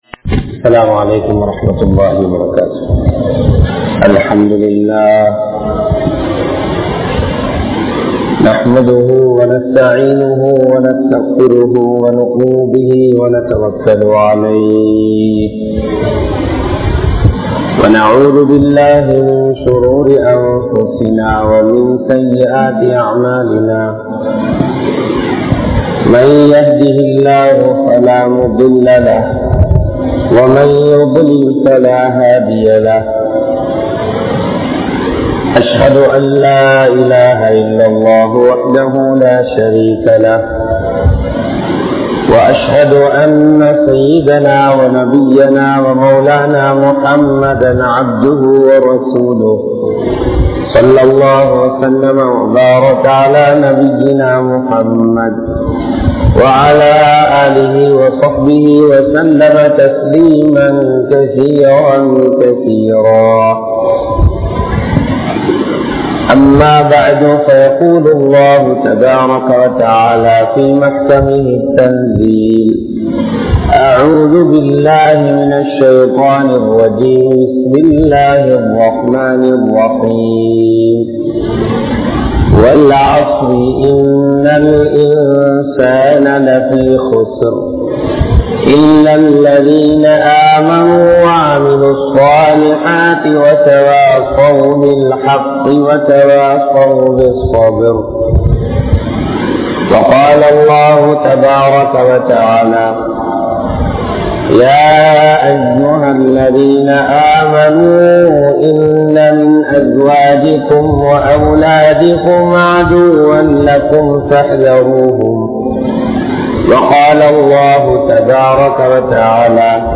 Ulaham Innum Paarkaatha Fithnah (உலகம் இன்னும் பார்க்காத பித்னா) | Audio Bayans | All Ceylon Muslim Youth Community | Addalaichenai
Dehiwela, Muhideen (Markaz) Jumua Masjith